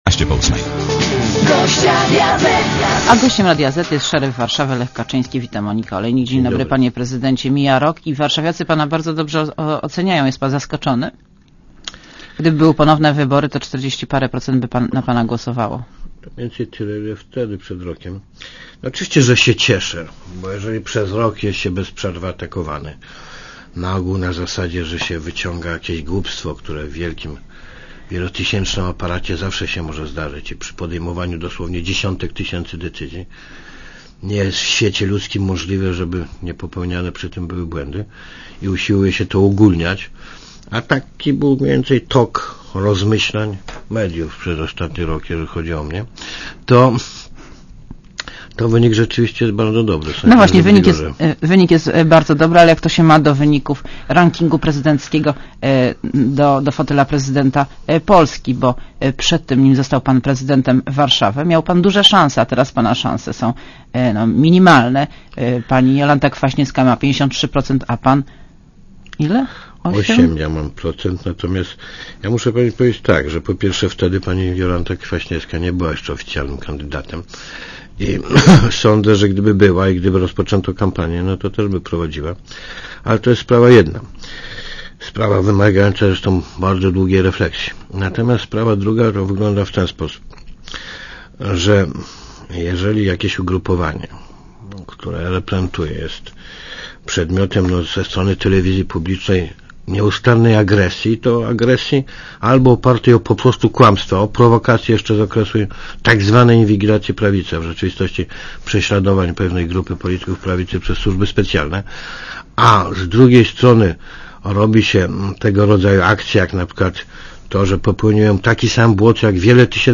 © (RadioZet) Posłuchaj wywiadu (3 MB) Gościem Radia Zet jest szeryf Warszawy, Lech Kaczyński.